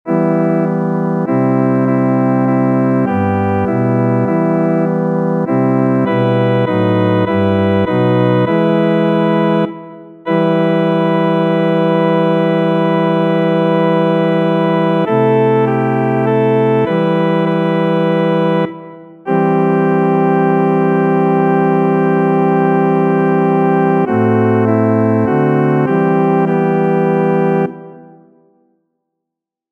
Title: C - 30. neděle v mezidobí Composer: Karel Bříza Arranger: Vojtěch Ulrich Number of voices: 1v Voicings: S or T Genre: Sacred, Responsory
Language: Czech Instruments: Organ